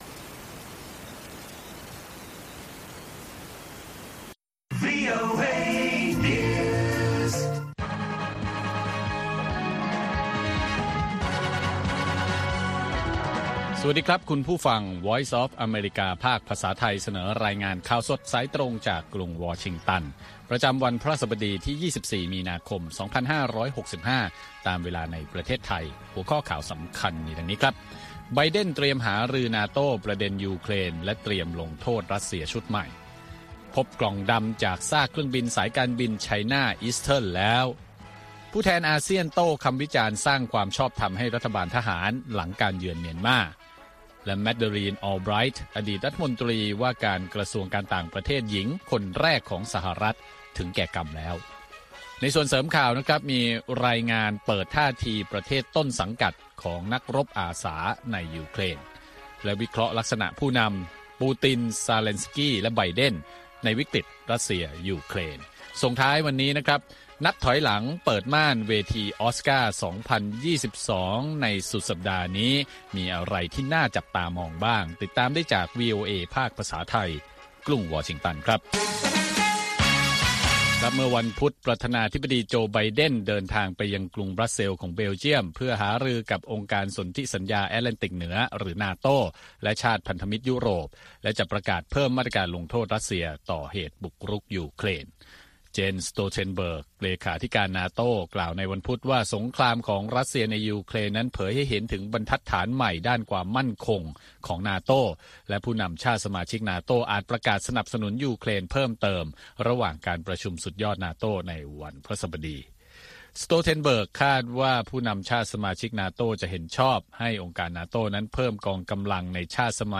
ข่าวสดสายตรงจากวีโอเอ ภาคภาษาไทย 8:30–9:00 น. ประจำวันพฤหัสบดีที่ 24 มีนาคม 2565 ตามเวลาในประเทศไทย